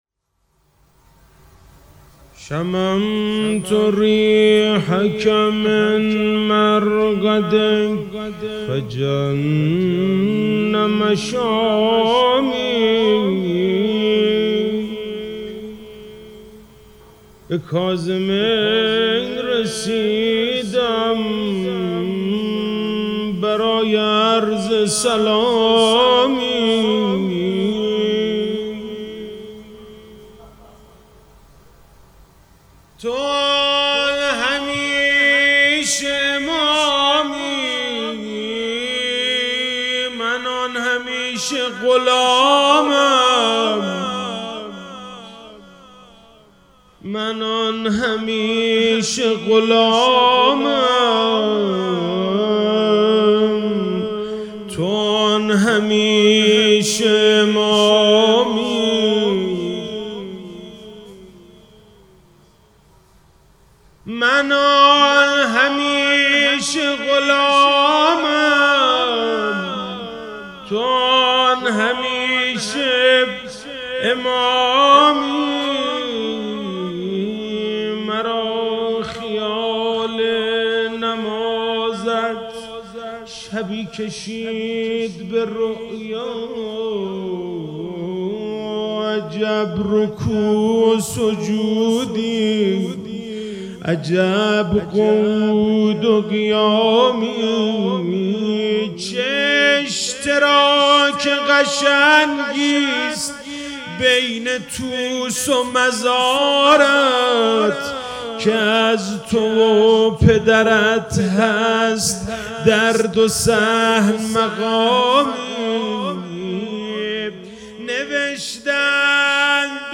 45186روضه-امام-جواد-علیه-السلام.mp3